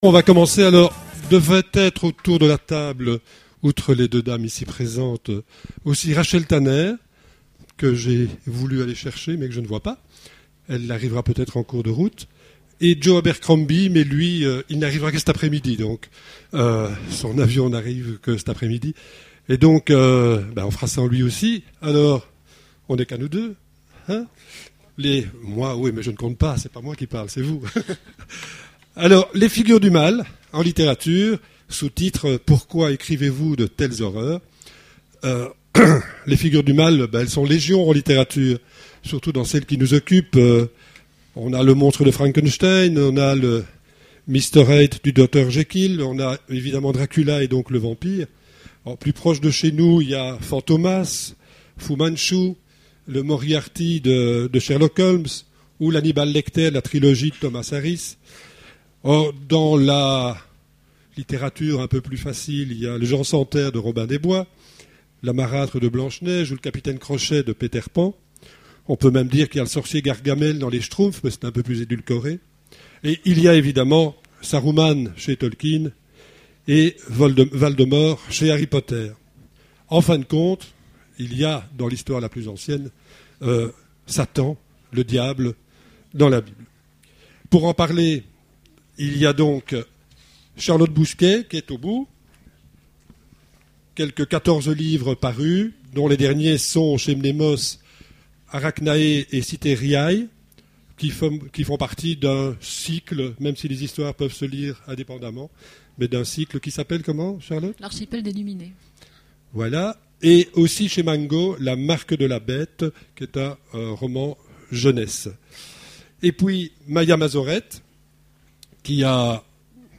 Conférence Imaginales 2010 : Pourquoi écrivez-vous de telles horreurs ? Les figures du mal en littérature…
Voici l'enregistrement de la conférence Pourquoi écrivez-vous de telles horreurs ? Les figures du mal en littérature…